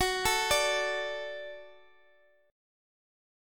Gbm#5 chord